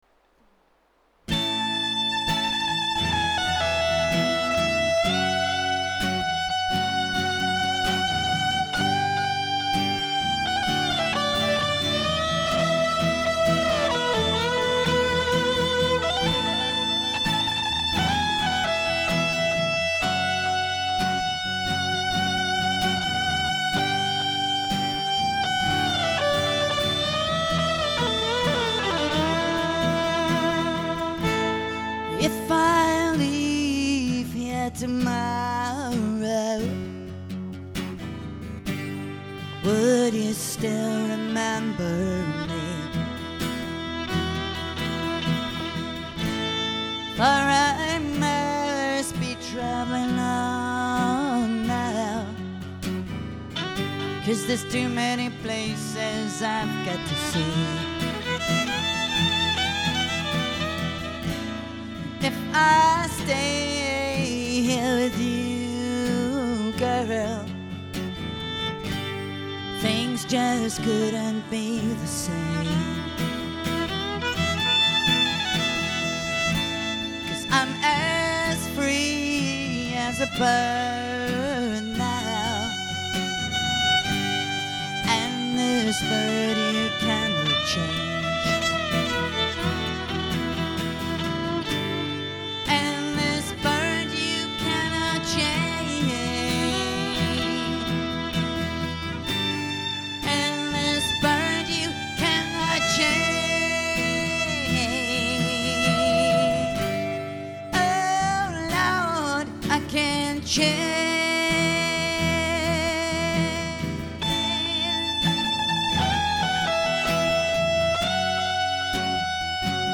acoustic guitar
electric violin
Audio Clips of the duo recorded live (MP3s):-